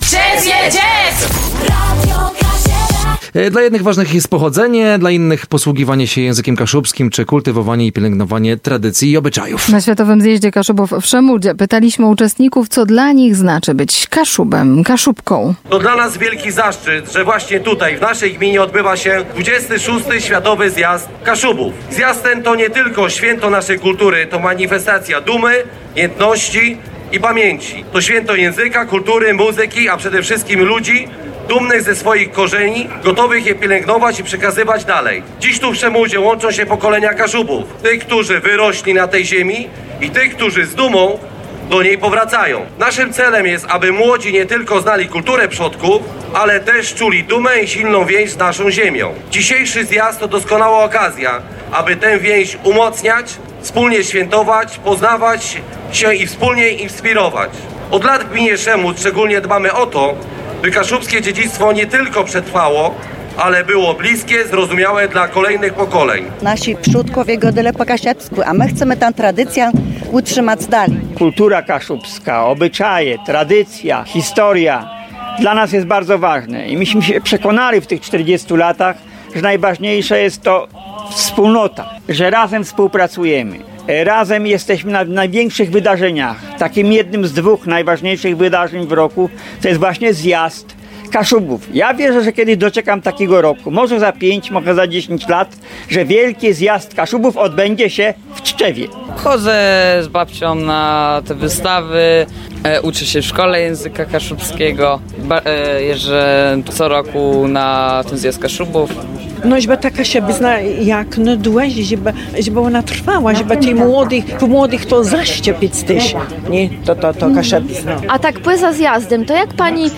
felieton-zjazd-kaszubow-szemud.mp3